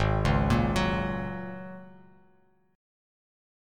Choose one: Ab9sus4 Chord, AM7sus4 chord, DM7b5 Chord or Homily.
Ab9sus4 Chord